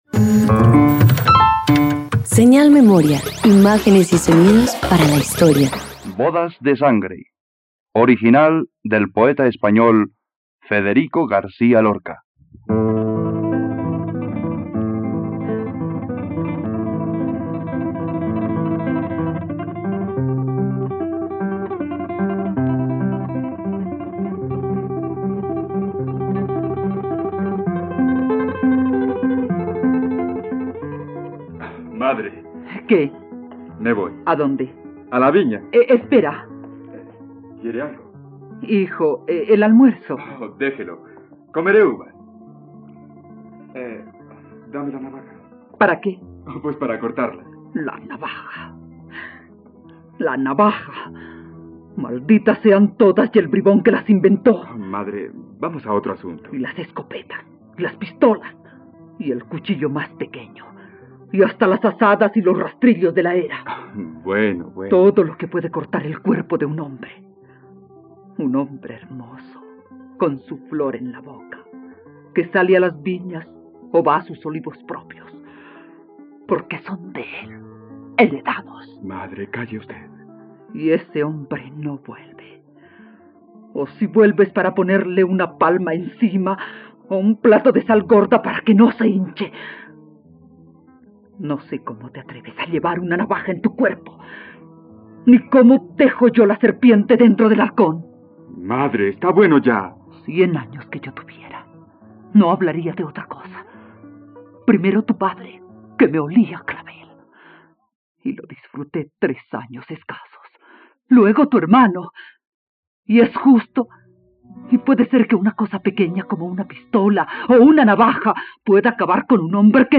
Bodas de sangre - Radioteatro dominical | RTVCPlay
..Radioteatro. Escucha la adaptación radiofónica de “Bodas de sangre” de Federico Garcia por la plataforma streaming RTVCPlay.